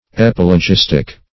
Search Result for " epilogistic" : The Collaborative International Dictionary of English v.0.48: Epilogistic \Ep`i*lo*gis"tic\, a. [Cf. Gr.